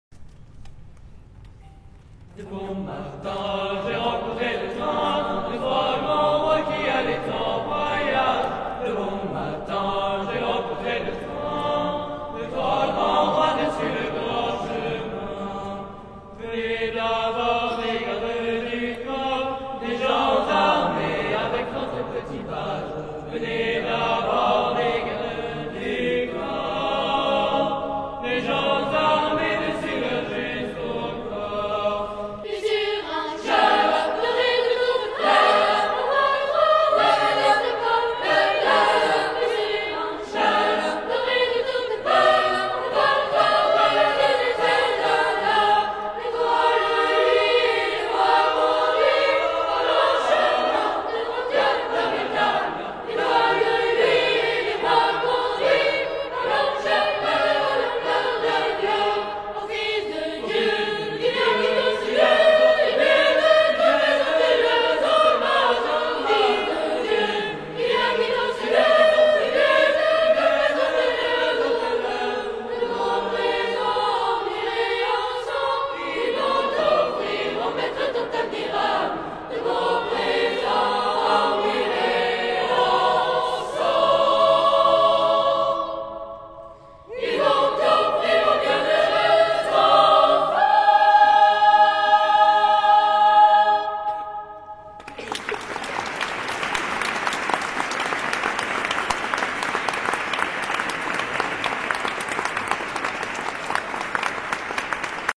Chœur d’hommes fondé en 1860
Chant de Noël provençal
H10440-Live.mp3